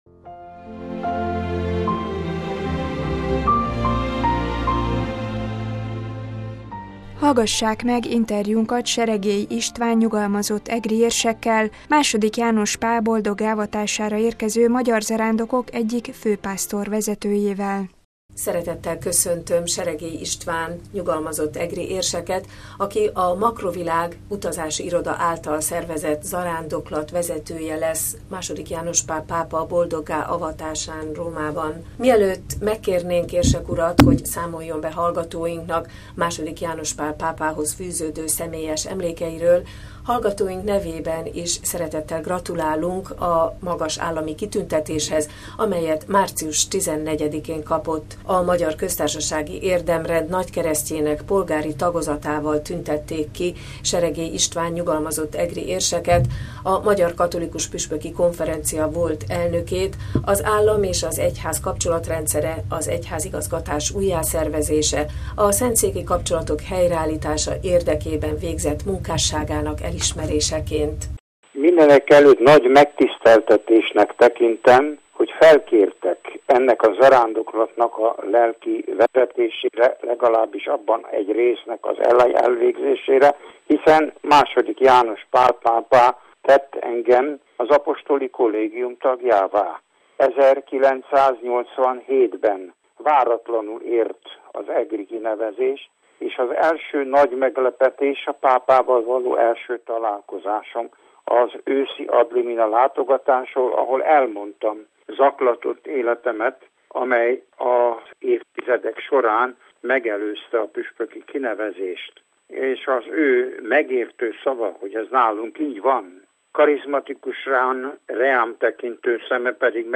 „Subito santo” – Legyen azonnal szent! – Interjú Seregély István ny. egri érsekkel